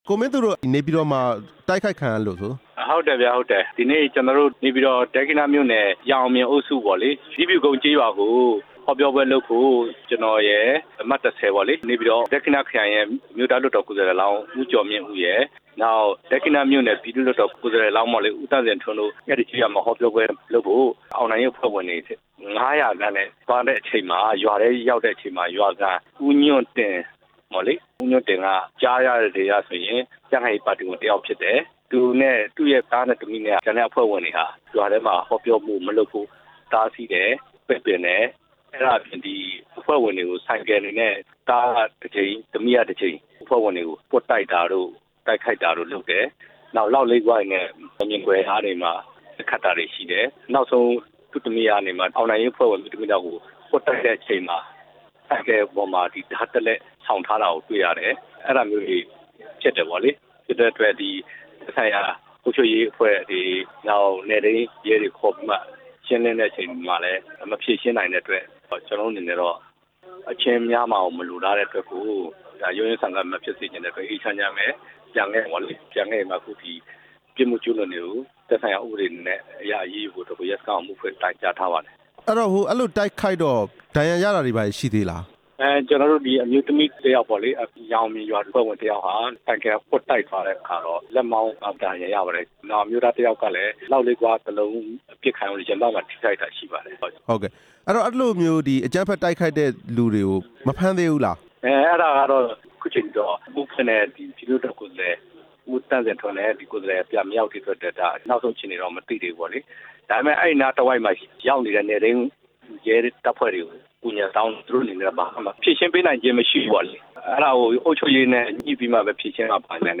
NLD လွှတ်တော်ကိုယ်စားလှယ် ဦးမင်းသူနဲ့ မေးမြန်းချက်